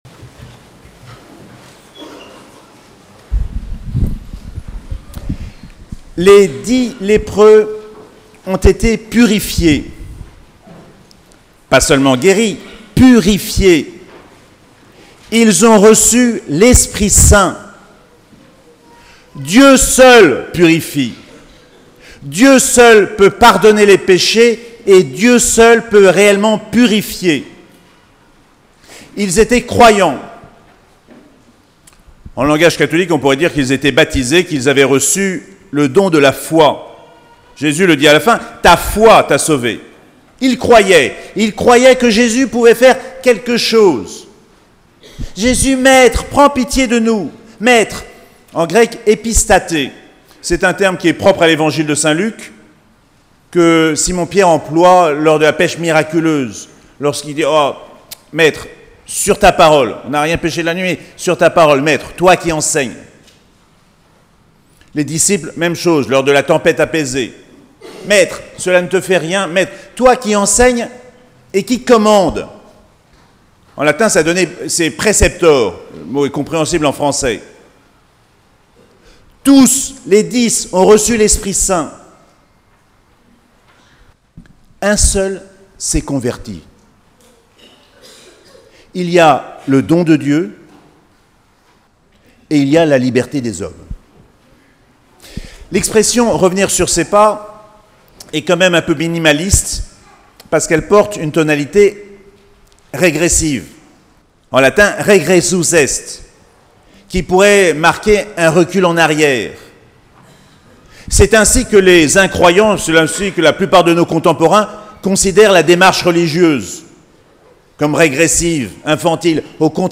28ème dimanche du Temps Ordinaire - 13 octobre 2019